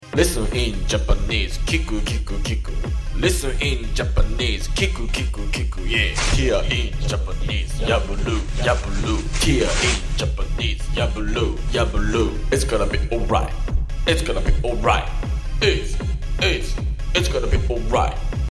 Japanese lesson with Music🇯🇵 Please sound effects free download